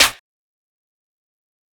DB - Claps & Snares (8).wav